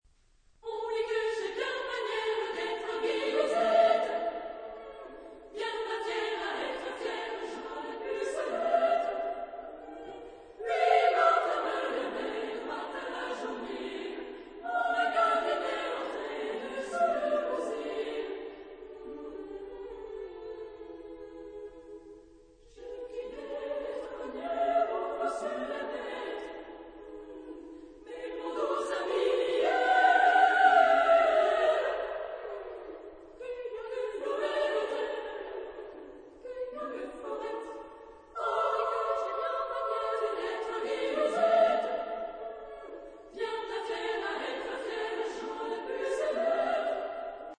Genre-Stil-Form: zeitgenössisch ; Liedsatz ; weltlich
Chorgattung: SSA  (3 Kinderchor ODER Frauenchor Stimmen )
Tonart(en): C-Dur